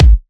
edm-kick-67.wav